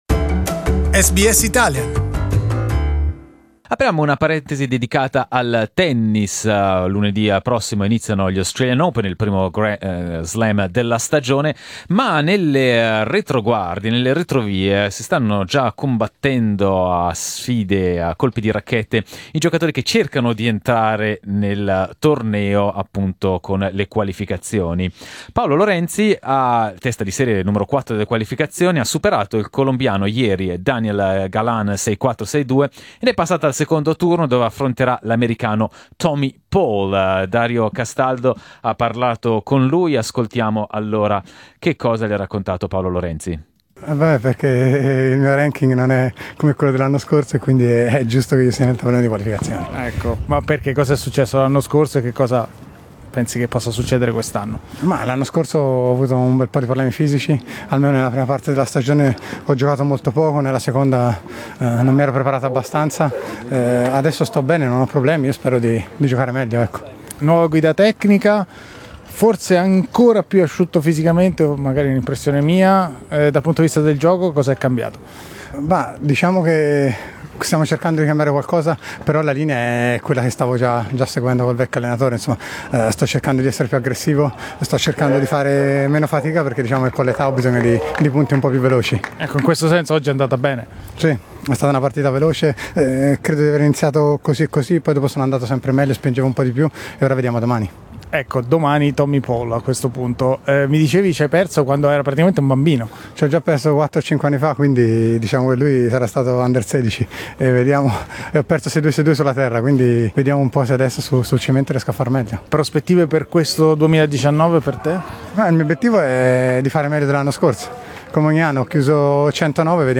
Le qualificazioni al torneo di Melbourne vedono una presenza record di italiani con la stessa speranza nel cuore (e nella racchetta): vedere il proprio nome nel tabellone principale. Le nostre interviste